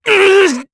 Clause_ice-Vox_Damage_jp_03.wav